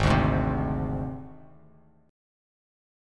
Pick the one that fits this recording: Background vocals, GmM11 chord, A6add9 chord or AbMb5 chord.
AbMb5 chord